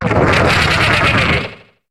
Cri d'Argouste dans Pokémon HOME.